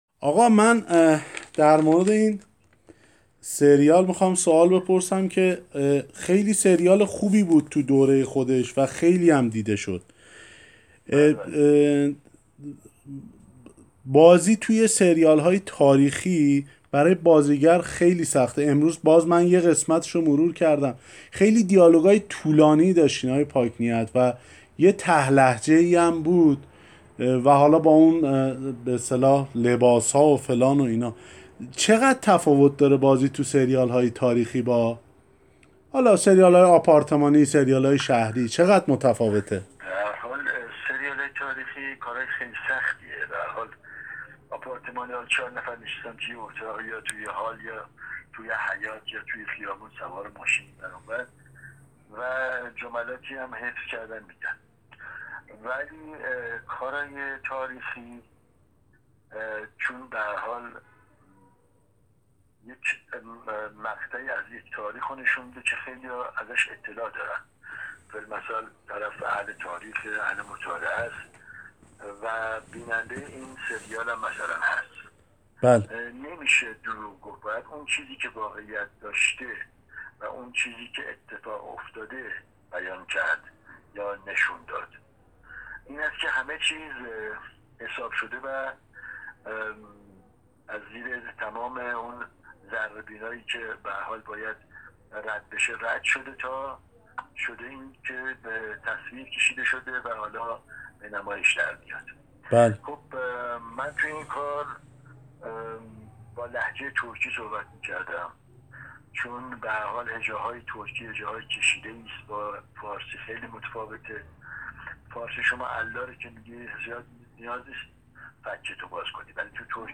محمود پاک‌ نیت بازیگری که در کارنامه هنری‌اش تجربه بازی در چندین نقش‌ تاریخی را دارد در بخشی از گفت‌وگویش با خبرنگار جام‌جم در اینباره توضیح داد: بازی در آثار تاریخی سخت اما ماندگار است.
مصاحبه و گفتگو